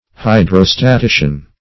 Search Result for " hydrostatician" : The Collaborative International Dictionary of English v.0.48: Hydrostatician \Hy`dro*sta*ti"cian\, n. One who is versed or skilled in hydrostatics.
hydrostatician.mp3